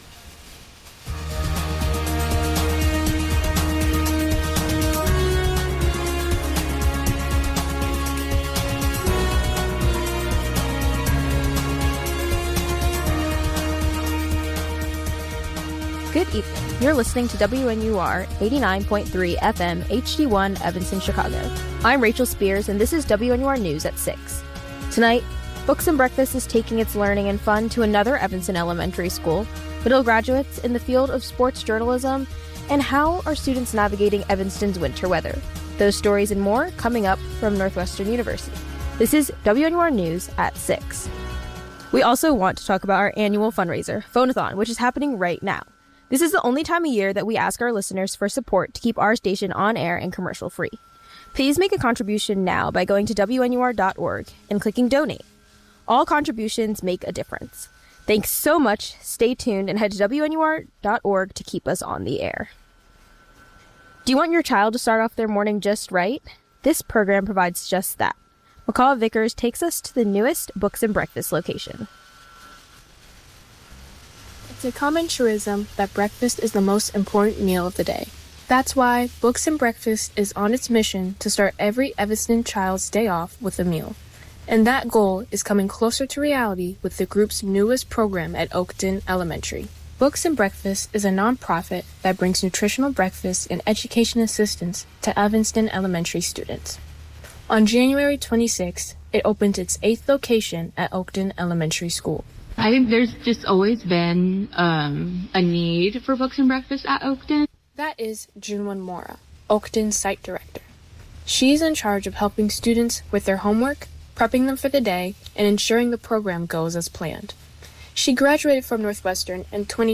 February 23, 2026: Books and Breakfast is taking its learning and fun to another Evanston elementary school, Medill graduates in the field of sports journalism, and how students are navigating Evanston’s winter weather. WNUR News broadcasts live at 6 pm CST on Mondays, Wednesdays, and Fridays on WNUR 89.3 FM.